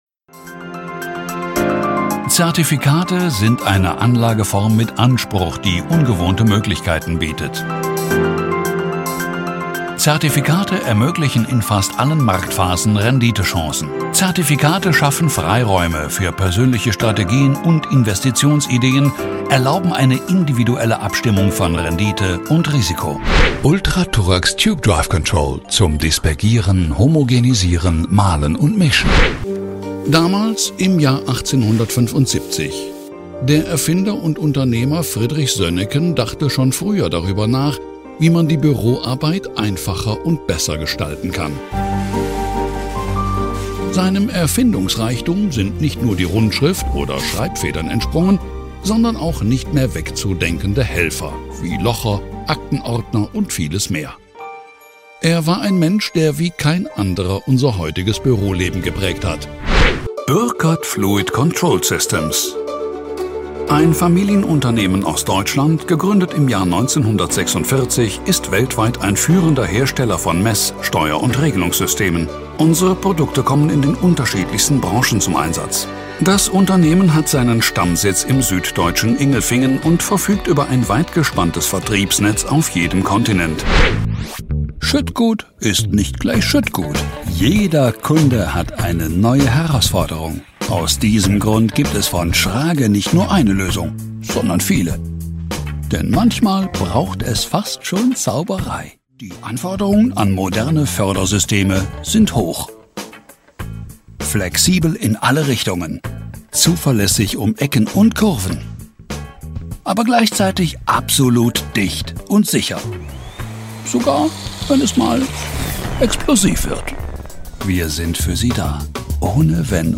Bekannte, dynamische, kräftige und markante Stimme; mit hohem Wiedererkennungswert in voller warmer Stimmlage.
Professioneller deutscher Sprecher (seit 1994) mit eigenem Studio.
Sprechprobe: Industrie (Muttersprache):
Industrie--Collage_Projekt.mp3